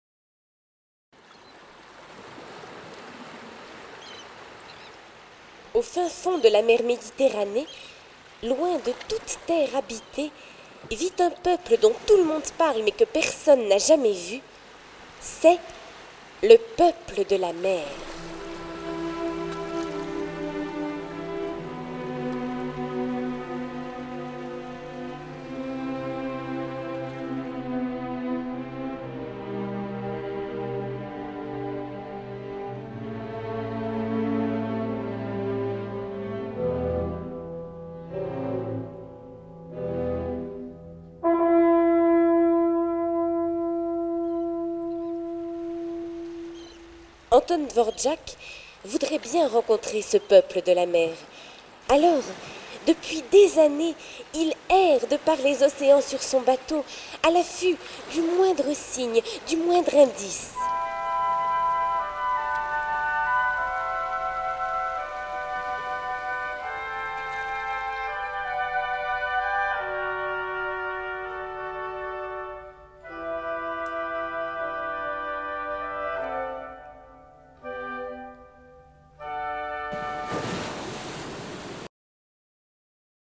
Contes audio, histoires racontées en musique - Éditions Mélopie
Écoutez avec plaisir 24 histoires pour enfants, des célèbres et des moins connues, toutes racontées au son des plus belles musiques classiques entremêlées de chansons écrites sur un extrait musical de chaque conte.
extrait-Contes-d-Andersen.mp3